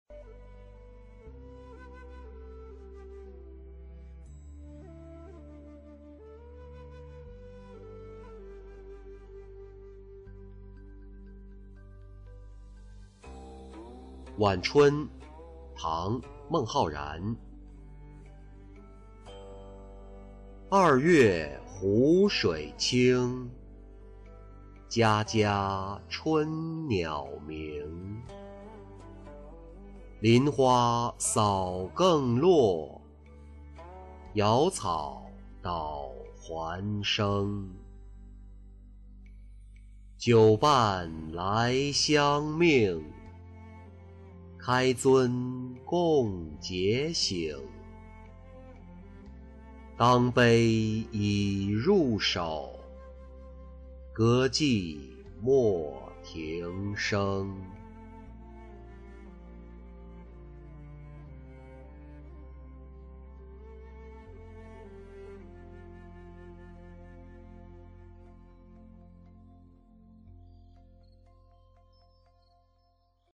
春中喜王九相寻-音频朗读